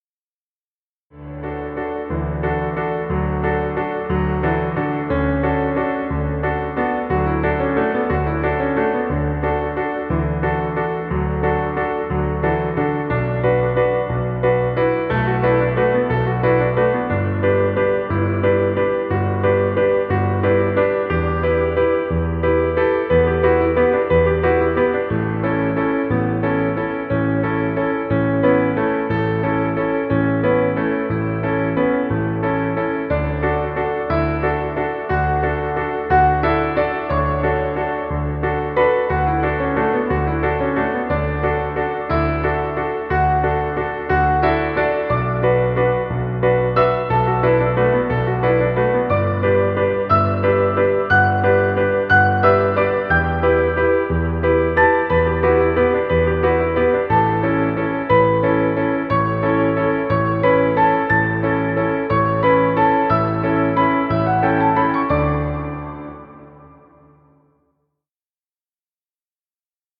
Piano music.